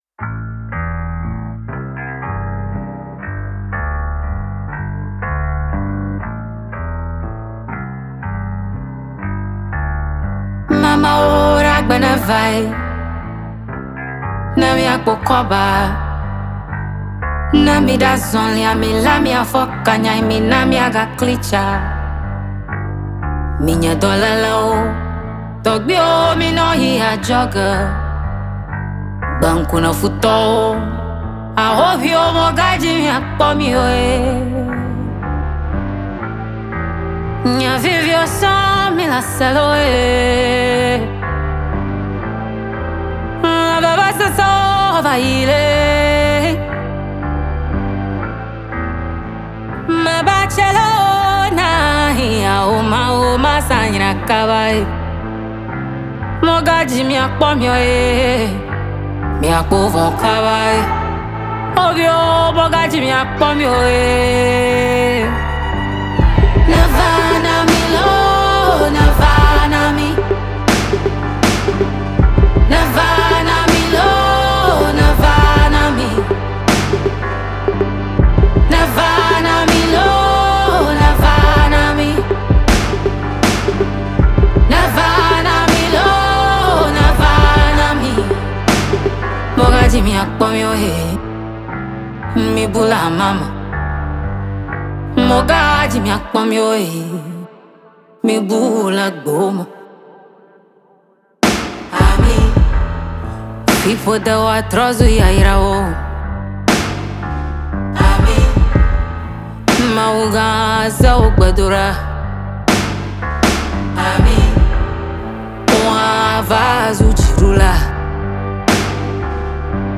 a very spiritual one by Ghanaian singer and writer